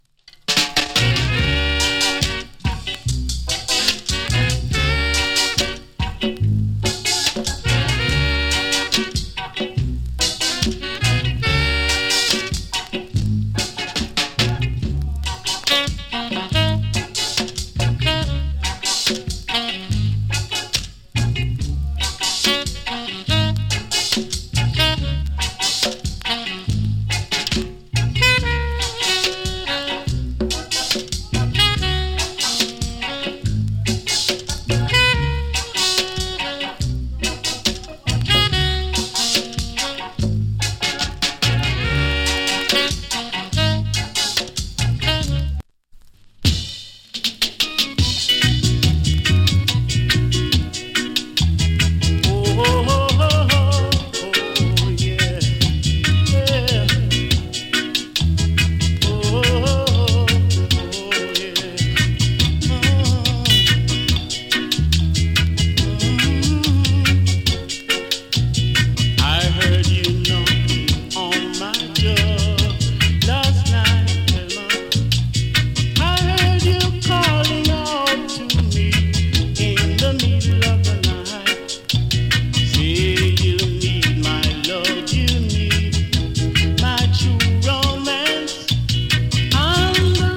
CONDITION A：VG+ / B：VG+ WOL. ＊スリキズ少し有り。チリ、パチノイズわずかに有り。
COMMENT STUDIO 1 RASTA INST !! & NICE VOCAL EARLY REGGAE !